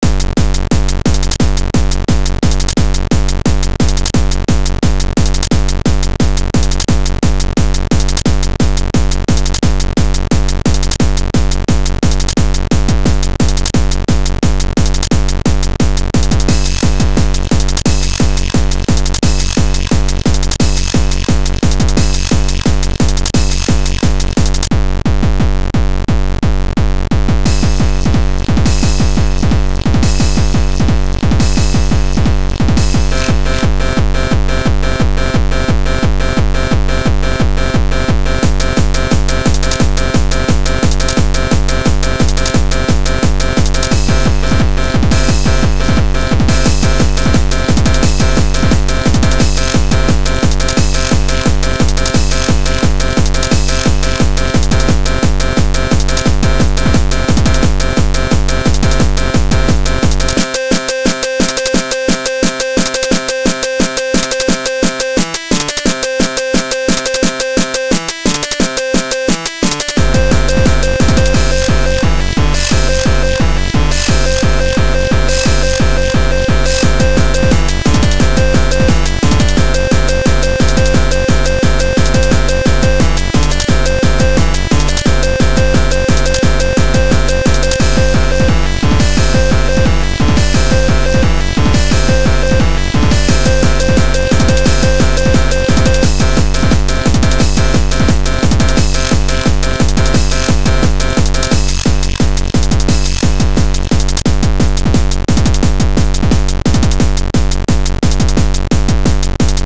Das lustige ist wie nahe Volksmusik und stupider, harter Techno einander sind.
Das ist halt mal härter, schneller und in einer Trinkpause ich glaub während der zweiten FreeRePublic entstanden.